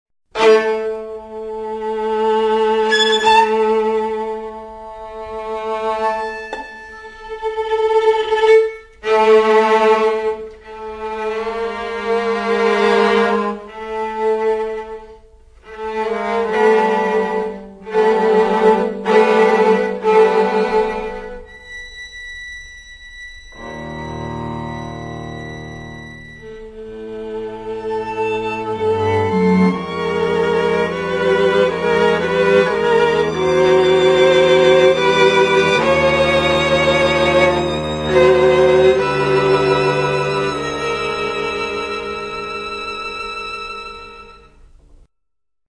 Aus dem Streichquartett